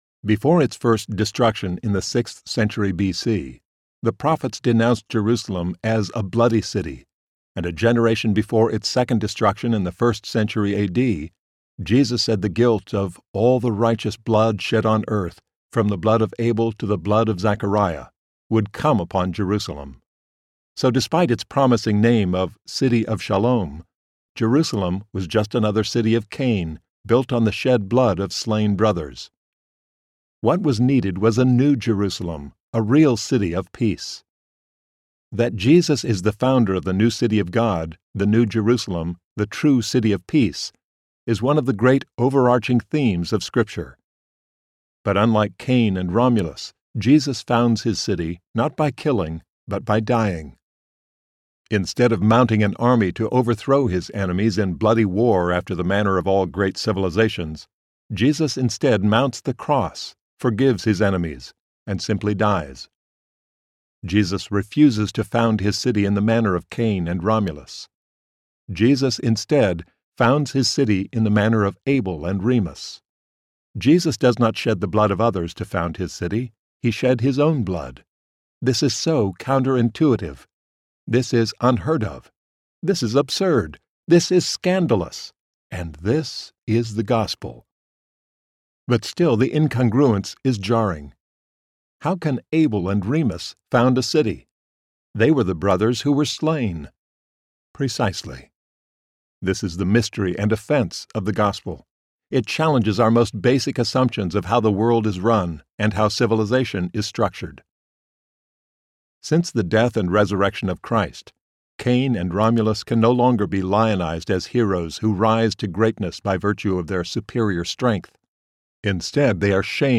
Beauty Will Save the World Audiobook
Narrator
6.98 Hrs. – Unabridged